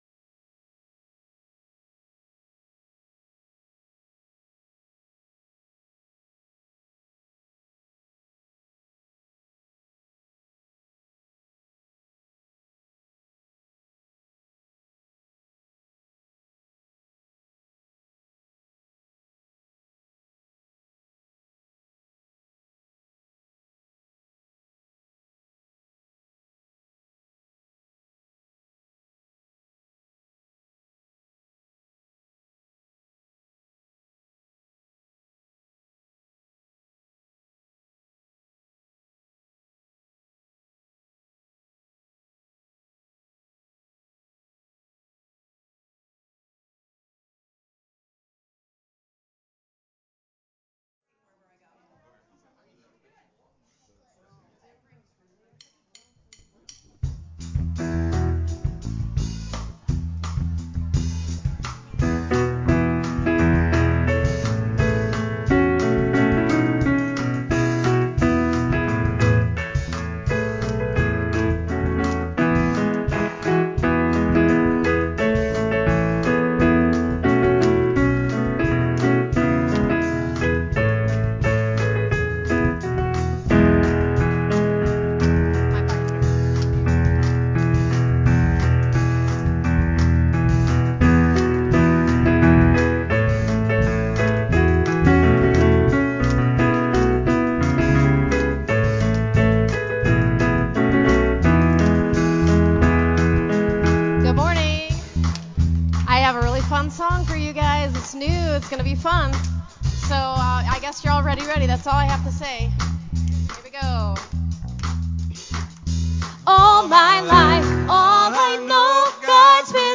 September 11 2022 Worship